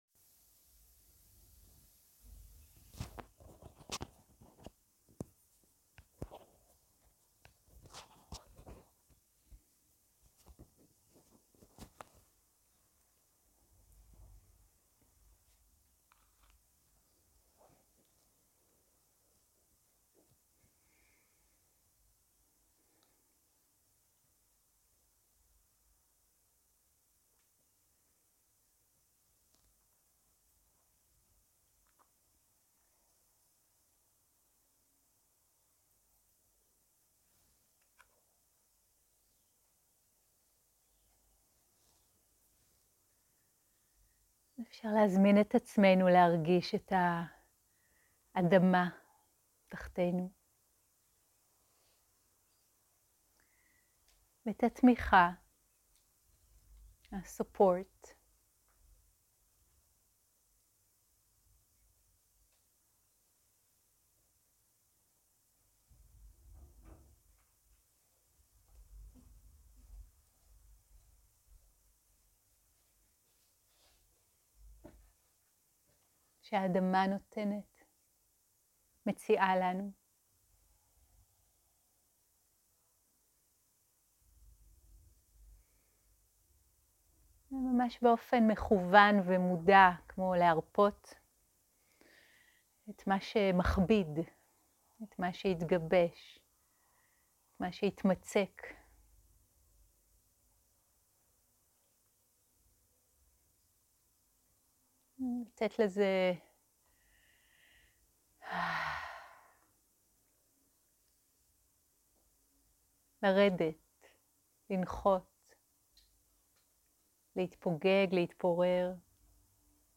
יום 2 - הקלטה 2 - בוקר - מדיטציה מונחית - מדיטציה עם אלמנט האדמה והספייס
יום 2 - הקלטה 2 - בוקר - מדיטציה מונחית - מדיטציה עם אלמנט האדמה והספייס 0:00 0:00 סוג ההקלטה: Dharma type: Guided meditation שפת ההקלטה: Dharma talk language: Hebrew